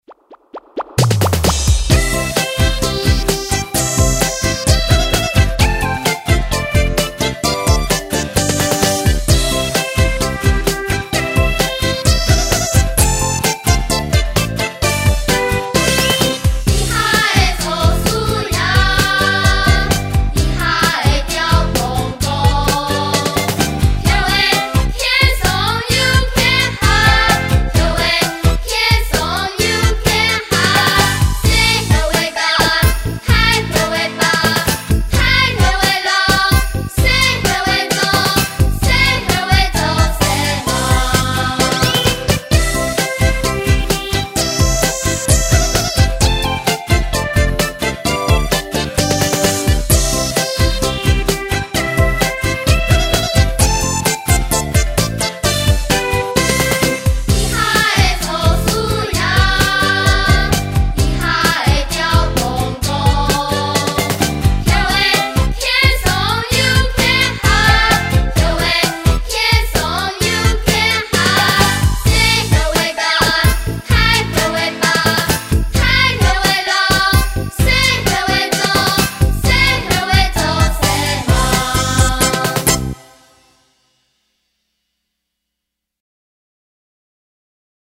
猴仔(完整演唱版) | 新北市客家文化典藏資料庫